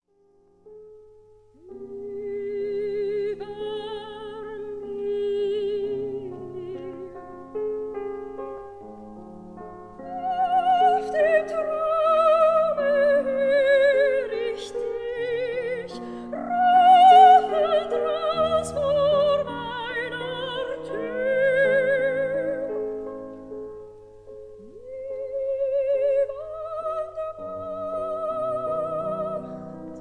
soprano
piano
Sofiensaal, Vienna